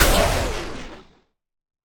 Minecraft Version Minecraft Version snapshot Latest Release | Latest Snapshot snapshot / assets / minecraft / sounds / mob / breeze / death2.ogg Compare With Compare With Latest Release | Latest Snapshot
death2.ogg